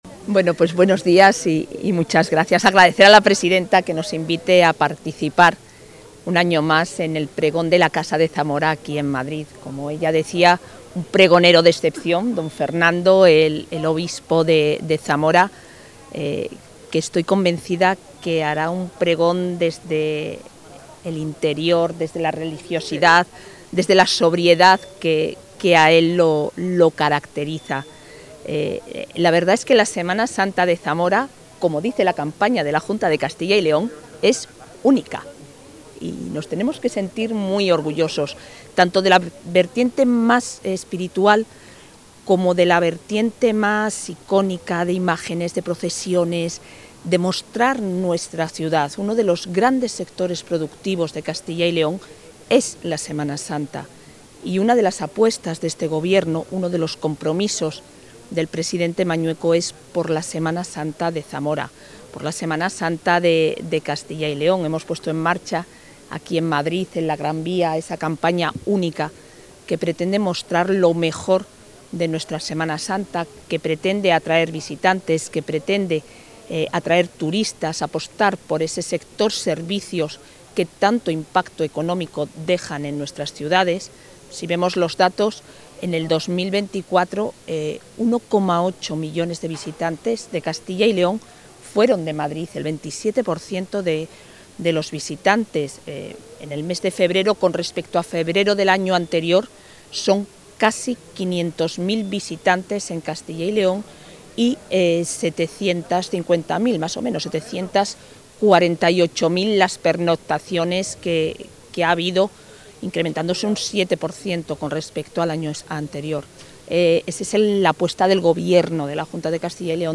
La vicepresidenta de la Junta asiste al Pregón de la Semana Santa de Zamora en Madrid
Declaraciones de la vicepresidenta de la Junta.